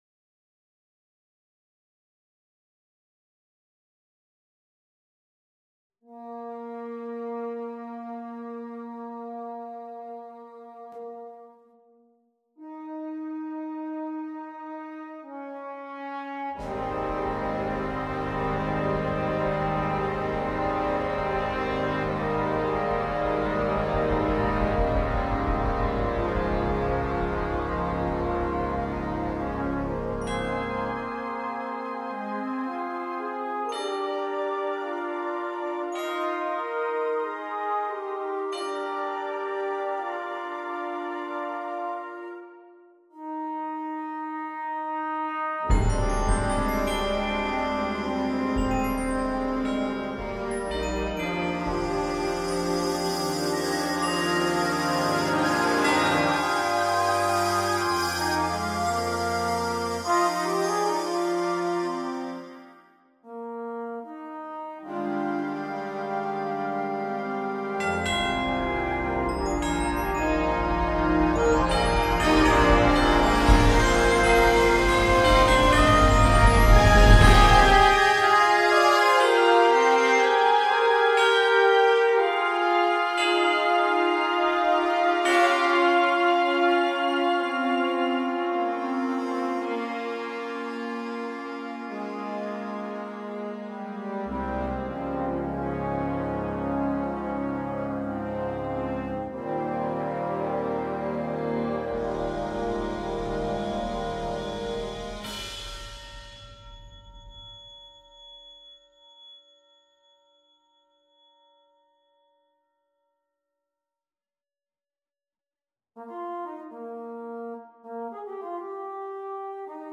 Instrumentation: Wind Ensemble